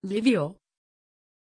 Aussprache von Lyvio
pronunciation-lyvio-tr.mp3